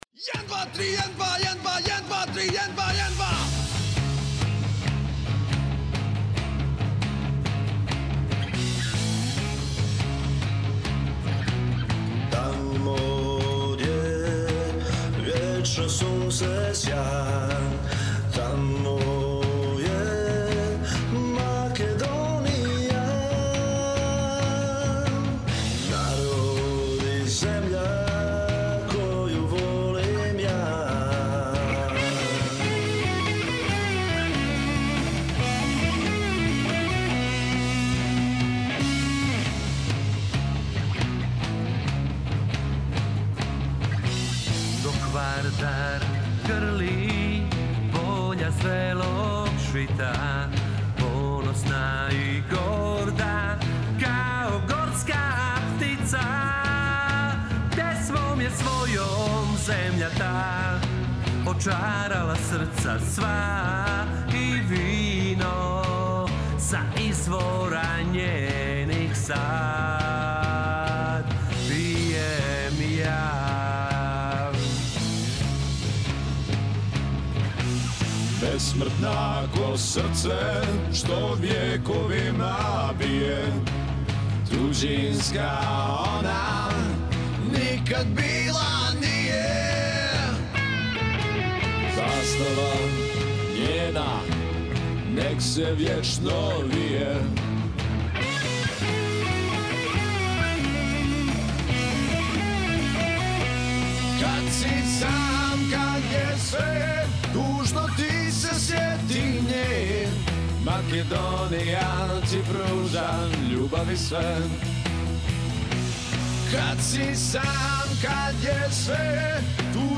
Pjesme su u LIVE izvedbi, u realnim atmosferama.